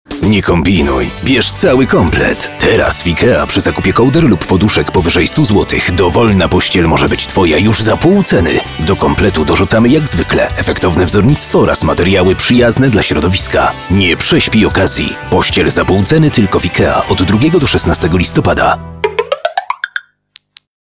Professioneller polnischer Sprecher für TV / Rundfunk / Industrie. Professionell voice over artist from Poland.
Sprechprobe: Industrie (Muttersprache):